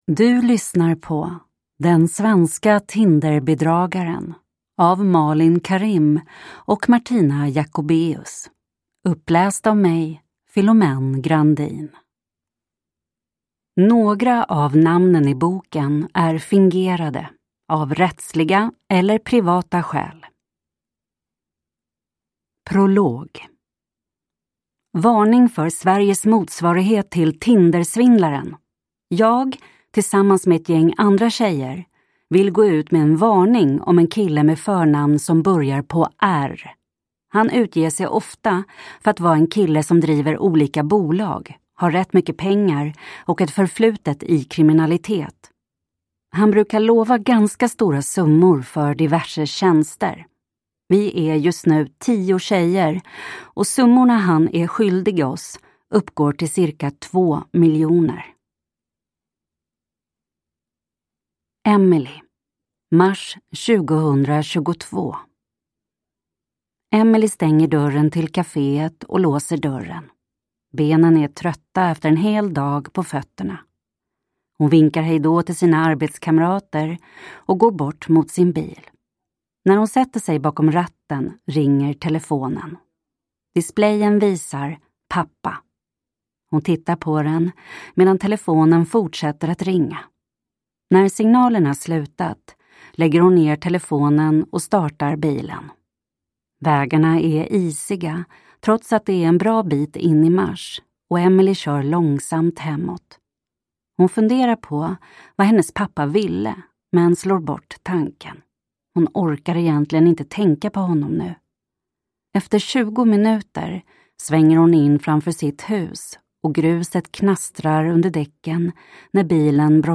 Ljudbok 145 kr